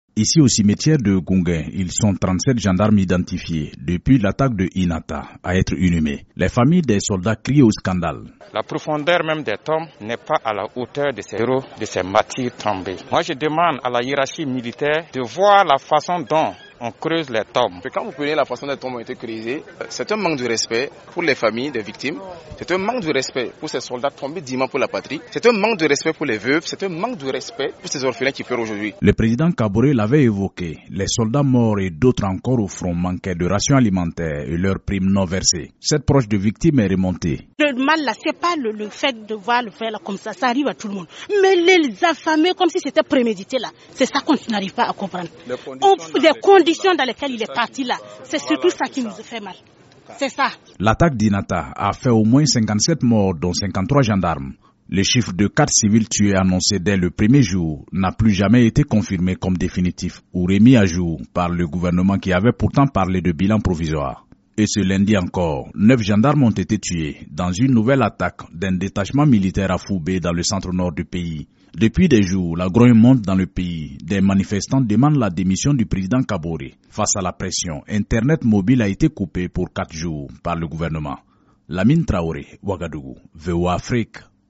Gendarmes tués au Faso: colère et indignation au cimetière
Au Burkina Faso 37 des 53 gendarmes tués le 14 novembre à Inata dans le Sahel ont été inhumés à Ouagadougou. Les parents de ces soldats morts étaient entre colère et indignation au cimetière.